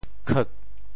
“乞”字用潮州话怎么说？